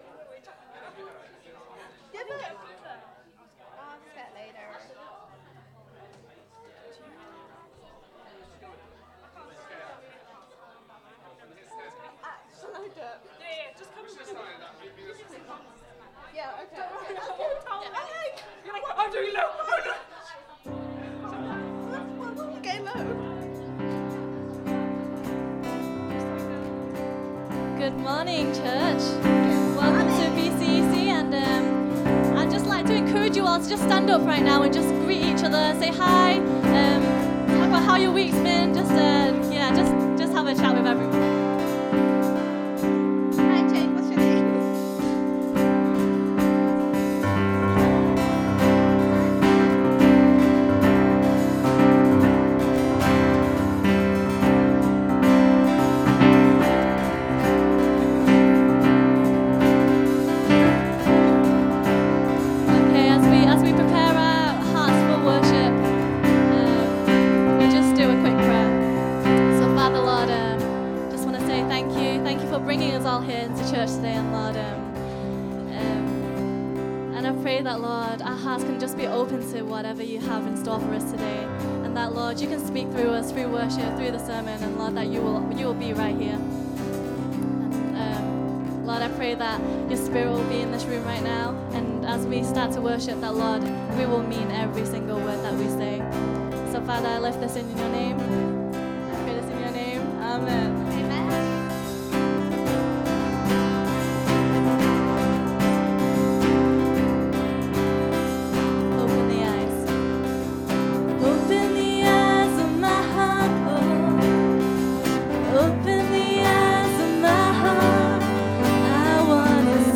Download Filename 130526Worship.mp3 filesize 47.09 MB Version 1.0 Date added 1 January 2015 Downloaded 1147 times Category Worship Sets Tags 2013